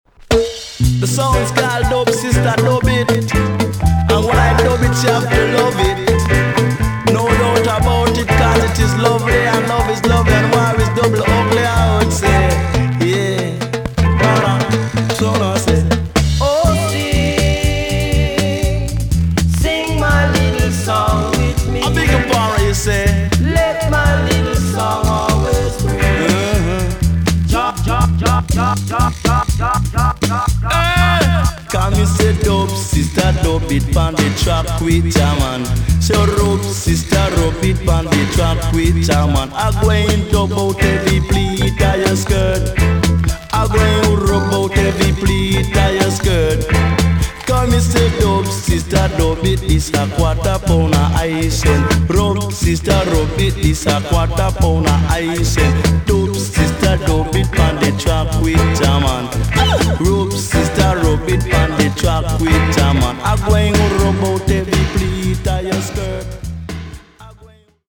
TOP >80'S 90'S DANCEHALL
EX-~VG+ 少し軽いチリノイズがありますが良好です。
1978 , UK , NICE DJ STYLE!!